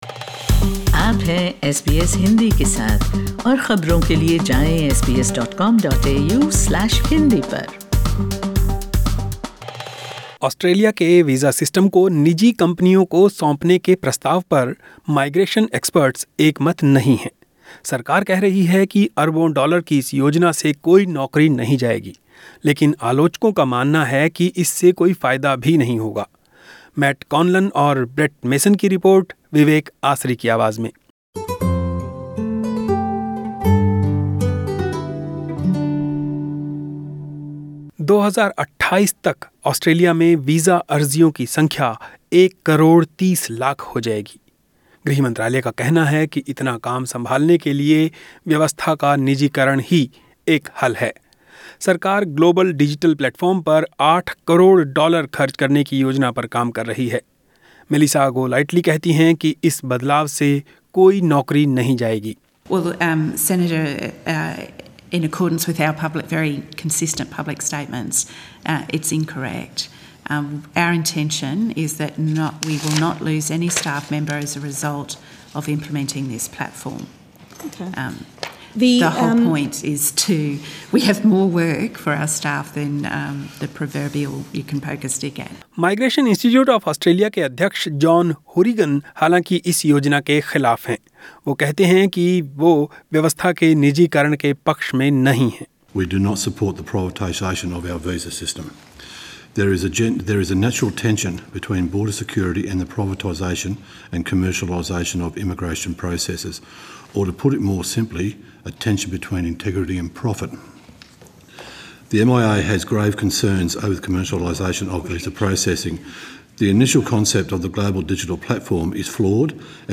Migration experts have warned a multi-billion-dollar plan to outsource visa processing is threatening the integrity of Australia's migration system. Listen to this report in Hindi.